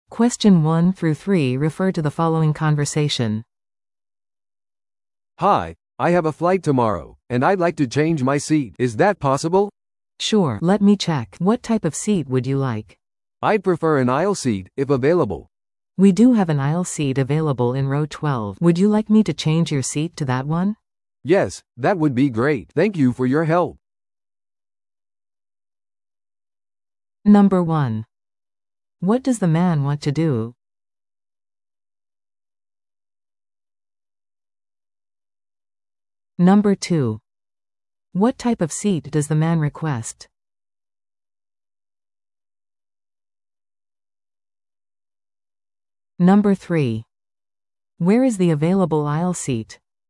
TOEICⓇ対策 Part 3｜飛行機の座席変更依頼 – 音声付き No.53